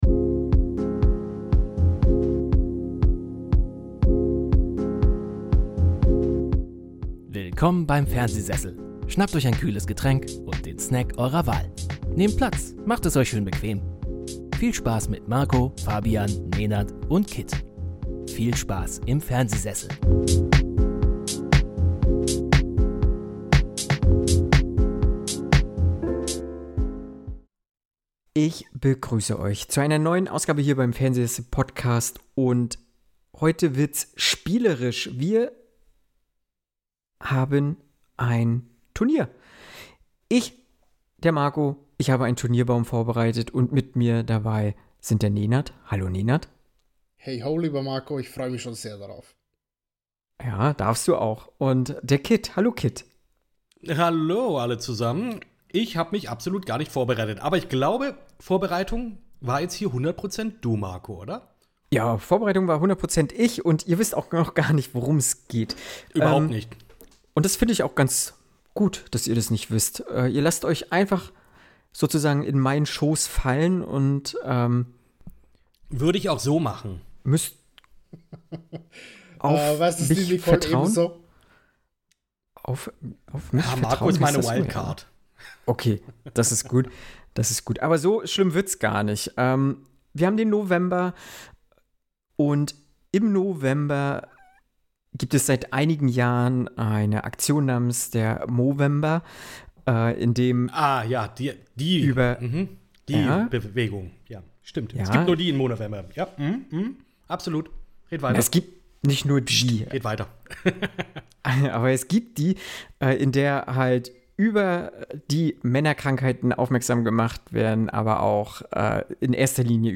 Eine Folge voller Nostalgie, Popkultur und gepflegtem Gesichtshaar – mit Diskussionen, Lachen und einer Prise Selbstironie.